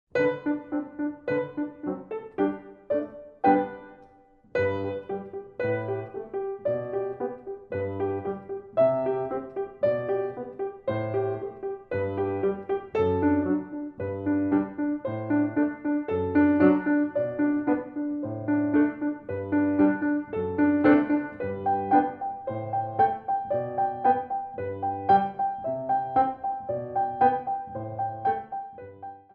Stacatto (faster repeat)